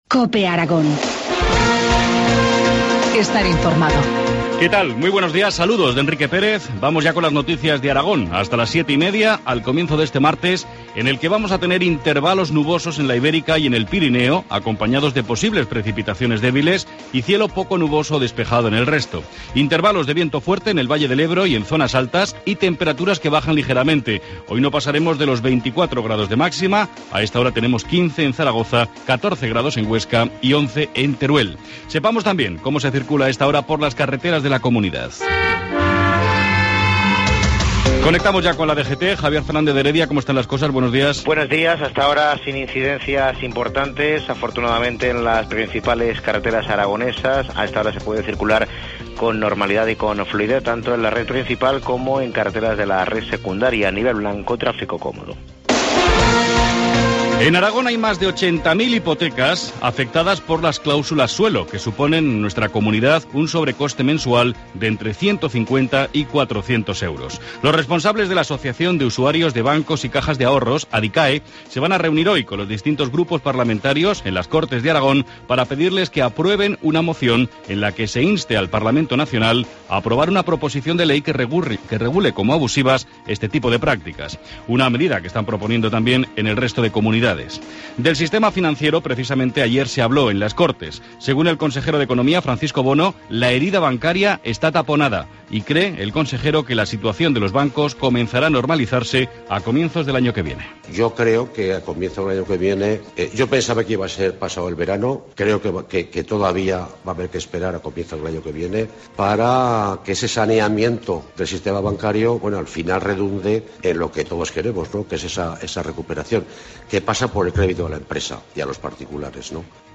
Informativo matinal, martes 17 de septiembre, 7.25 horas